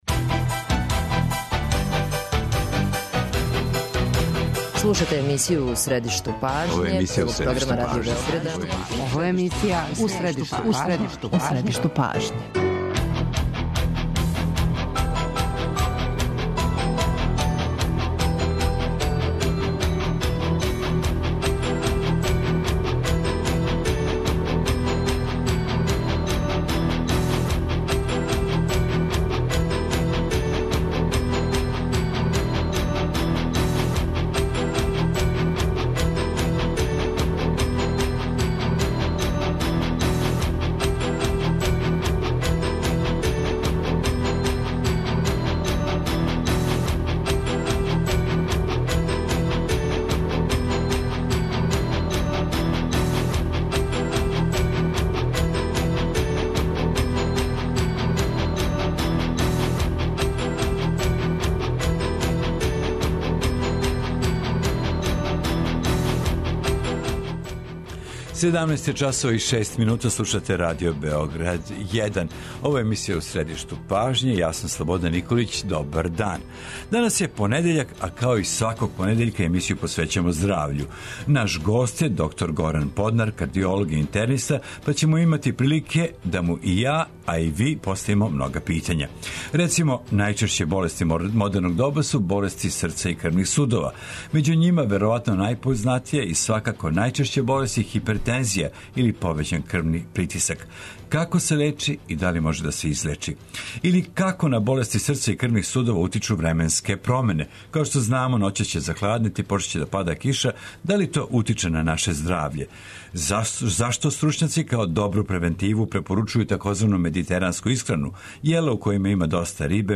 Он ће одговарати и на питања слушалаца о лечењу болести срца и крвних судова, као и о томе када се препоручују лекови, када уградња стента а када хируршка интервенција.